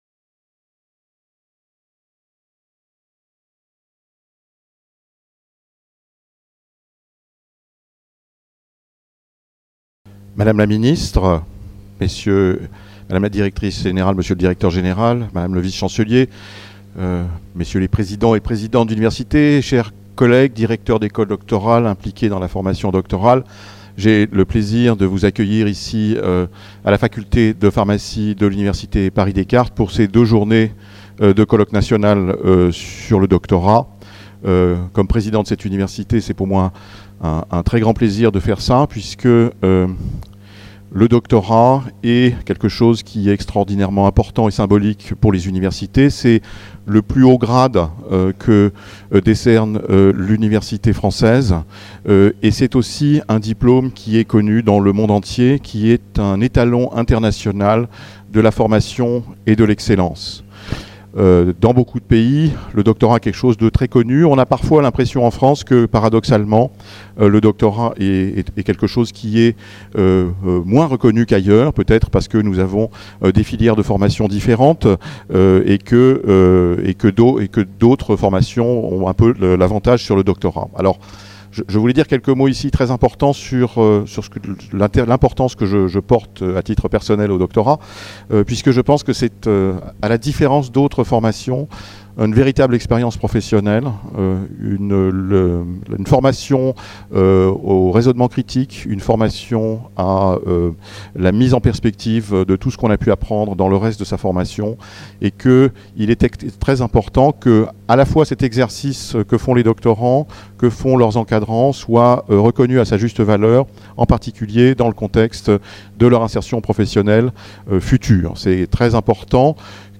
Ouverture Frédéric DARDEL, Président de l’Université Paris Descartes Séance introductive Najat VALLAUD-BELKACEM, ministre de l'Éducation nationale, de l'Enseignement supérieur et de la Recherche Adam TYSON, Adjoint du Directeur Général, DG Education et culture, Commission européenne